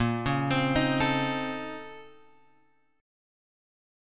A#mM11 chord {6 4 x 5 4 5} chord